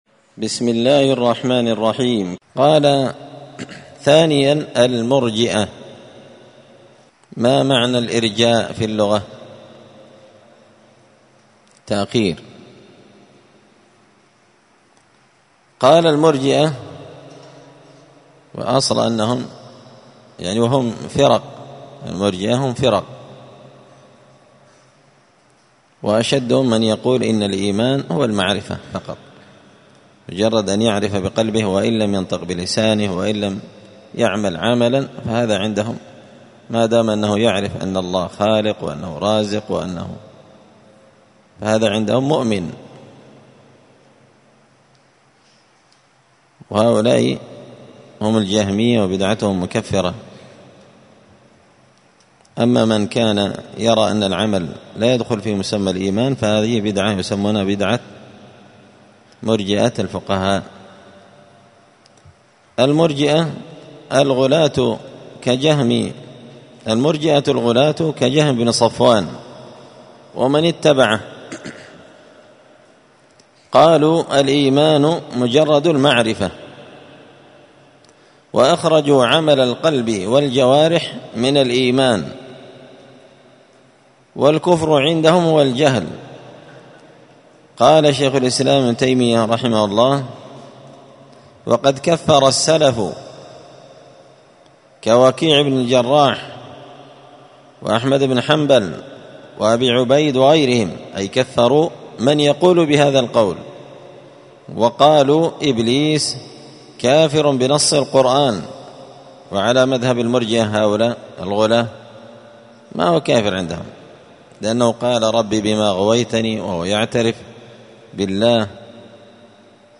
*الدرس العاشر (10) تابع لموجبات تضعيف الرواة*